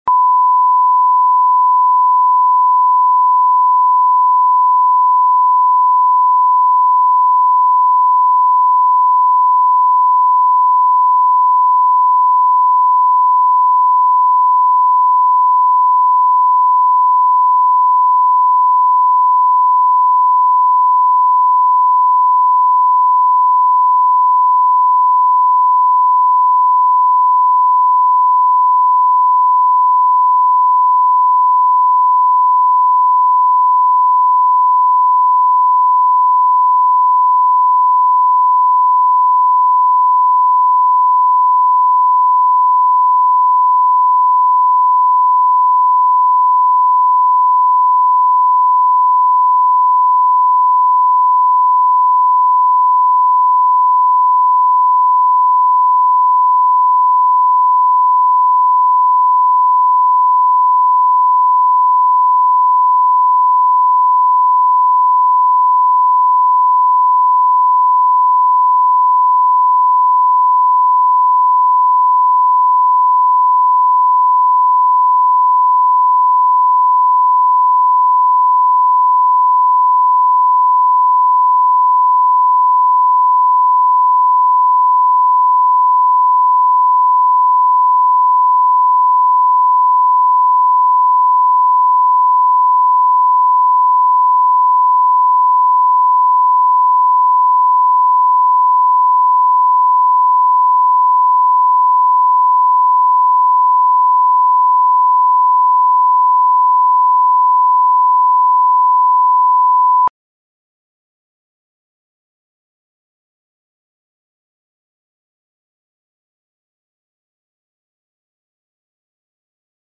Аудиокнига Кризис монотонности | Библиотека аудиокниг